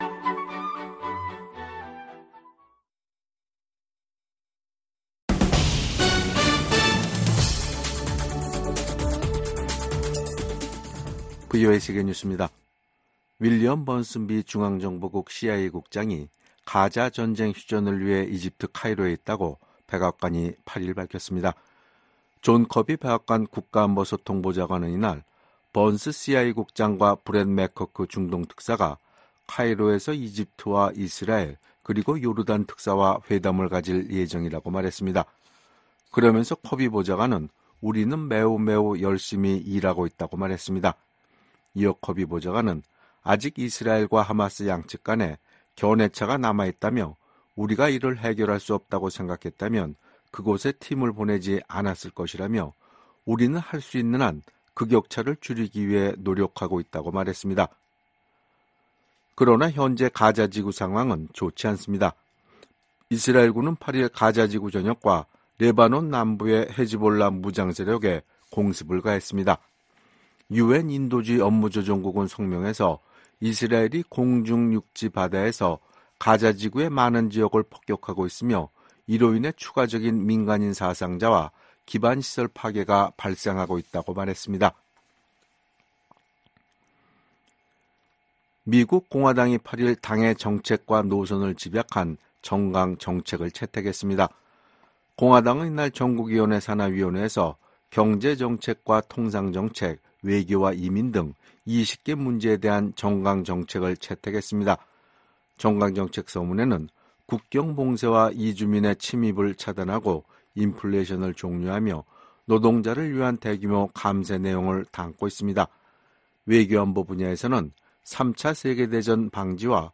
VOA 한국어 간판 뉴스 프로그램 '뉴스 투데이', 2024년 7월 9일 2부 방송입니다. 오늘 9일부터 11일까지 이곳 워싱턴에서는 32개국 지도자들이 참석하는 나토 정상회의가 열립니다.